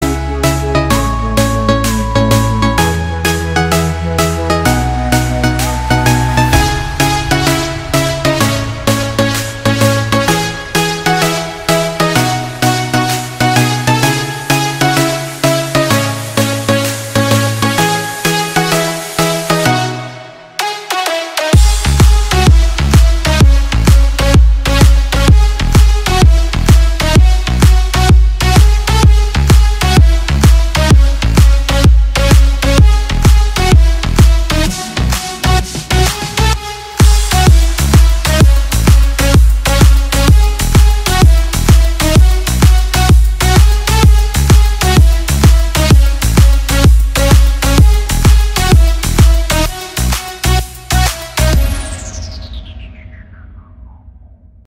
• Качество: 320, Stereo
remix
без слов
club
house
electro